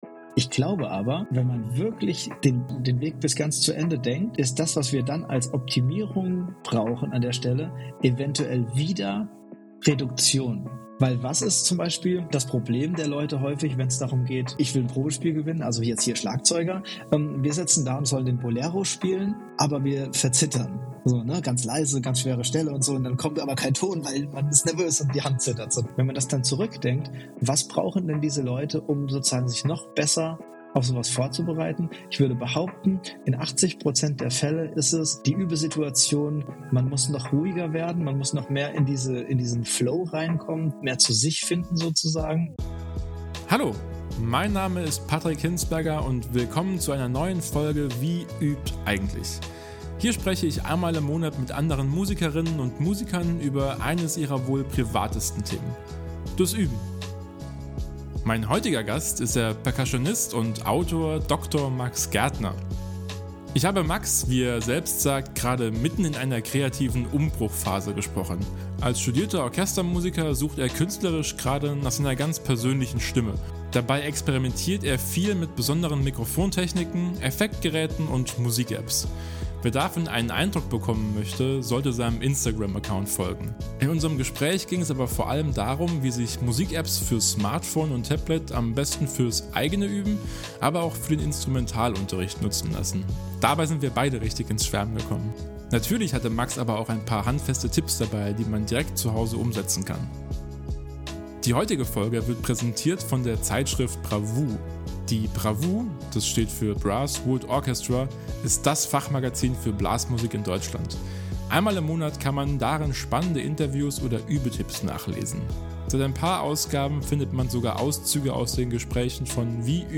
In unserem Gespräch ging es aber vor allem darum, wie sich Musik-Apps fürs Smartphone und Tablet am besten fürs eigene Üben - aber auch für den Instrumentalunterricht nutzen lassen.